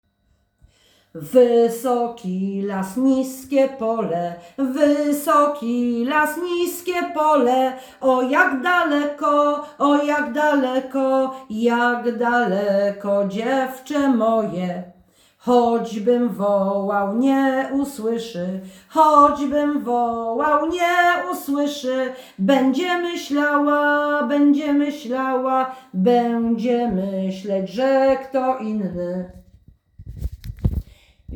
Nagranie współczesne